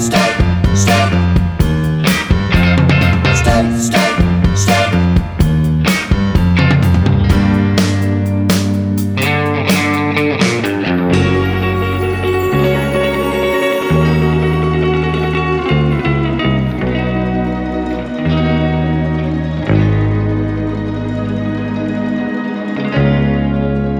no Backing Vocals Medleys 4:38 Buy £1.50